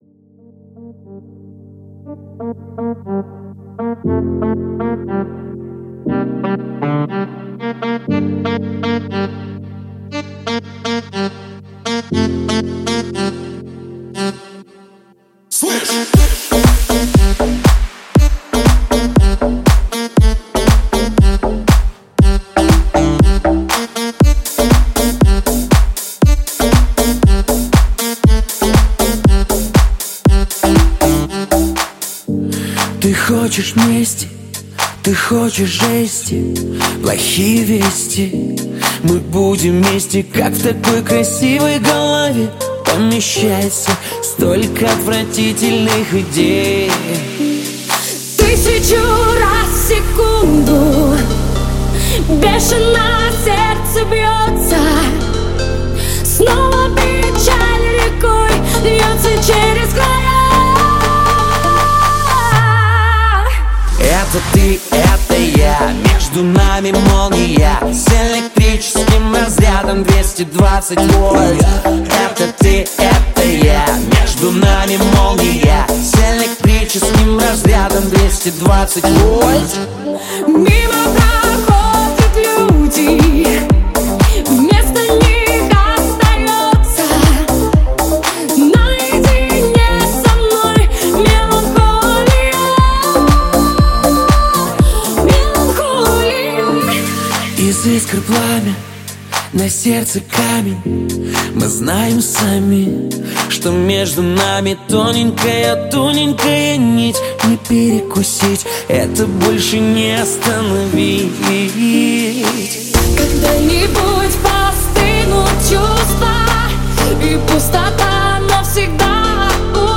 Поп-музыка